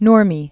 Nur·mi
(nrmē), Paavo 1897-1973.